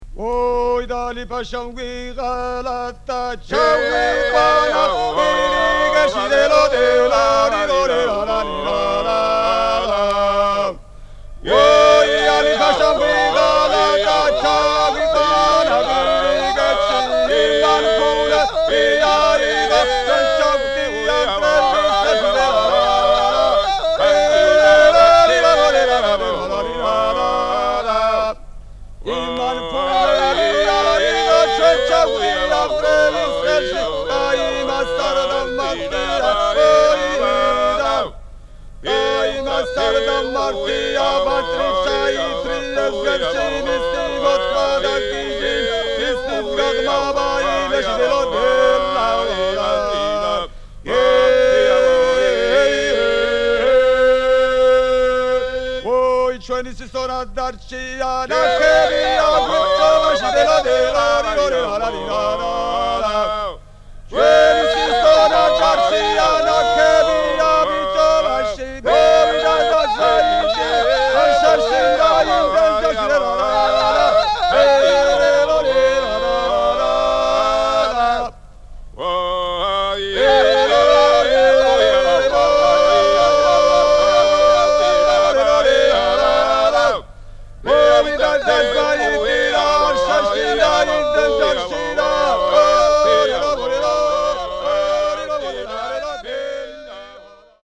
多声合唱の最も古い形のひとつと云われるジョージアの伝承歌。
異なる特徴を持つ各地域ごとの録音で構成。
現地録り